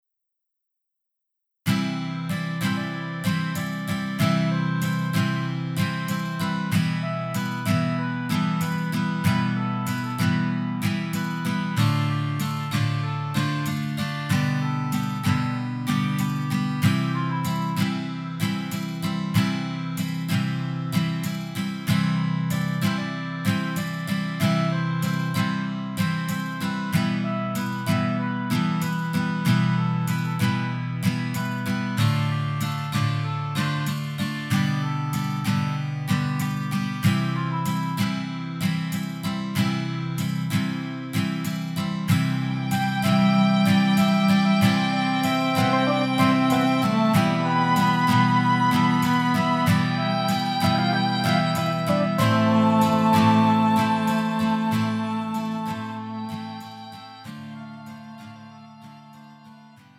음정 원키 2:31
장르 가요 구분 Pro MR